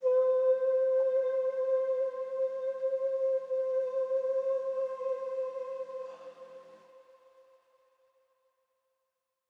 Dusty Vocal One Shot.wav